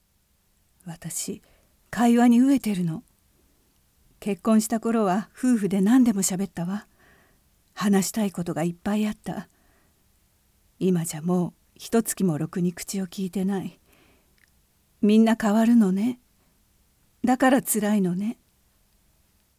セリフ2
ボイスサンプル